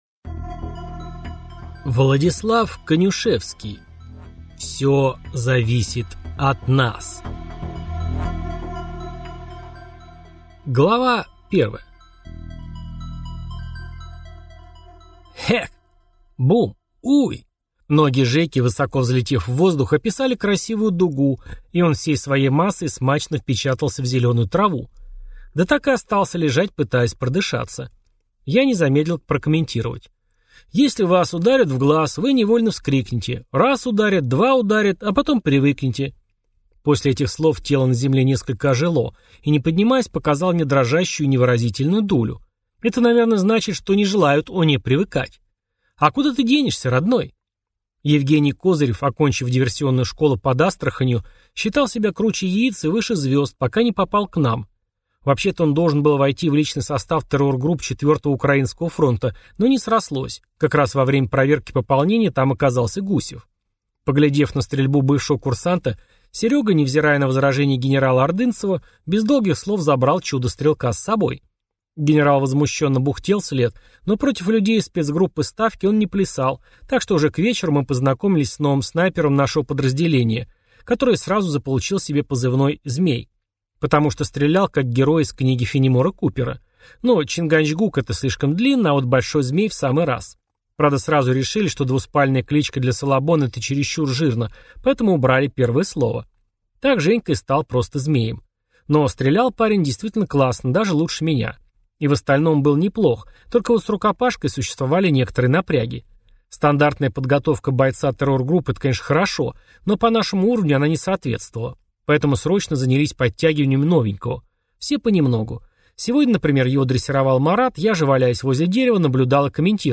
Аудиокнига Попытка возврата. Всё зависит от нас | Библиотека аудиокниг